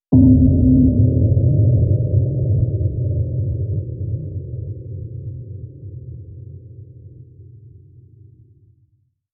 Sound-Objects
Electroacoustic
Experimental
HDD.LONG01.wav